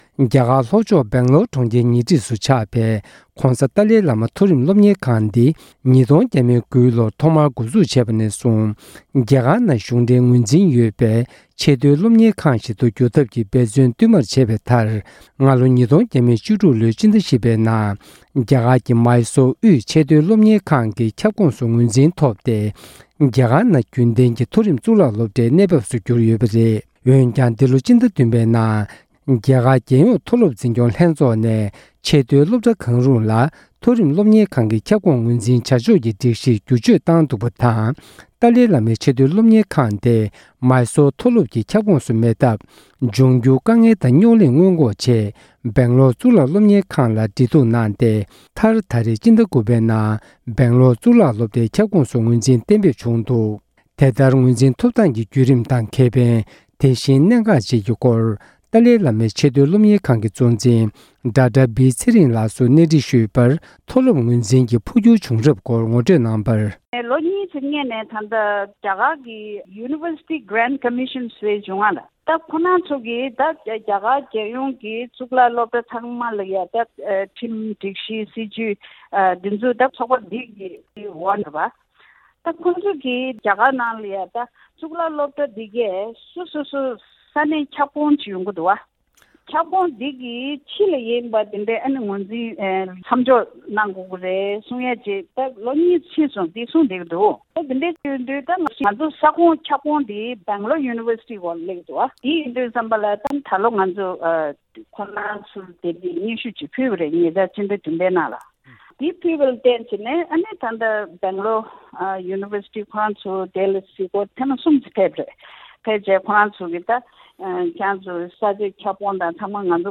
སྒྲ་ལྡན་གསར་འགྱུར། སྒྲ་ཕབ་ལེན།
བཅར་འདྲི